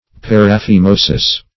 Meaning of paraphimosis. paraphimosis synonyms, pronunciation, spelling and more from Free Dictionary.
Search Result for " paraphimosis" : The Collaborative International Dictionary of English v.0.48: Paraphimosis \Par`a*phi*mo"sis\, n. [NL., fr. Gr.